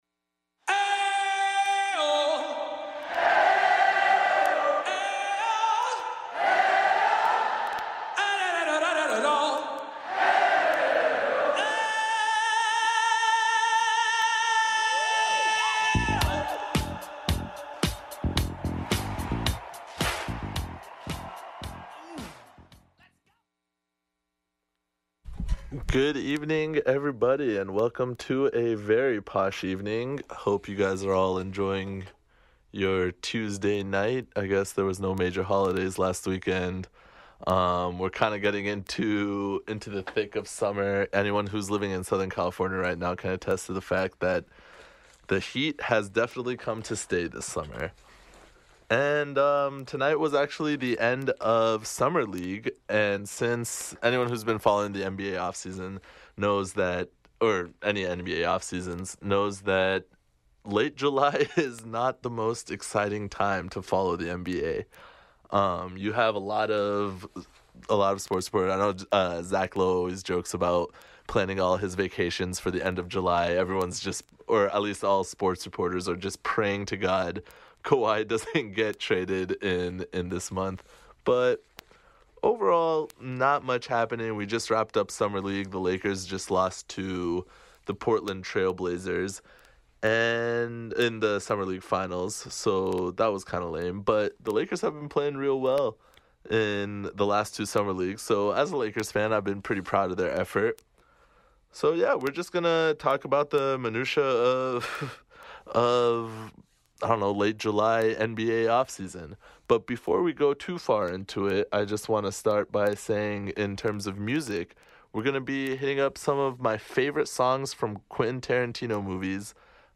*This is a recording of a live show from the non-profit station, KXSC.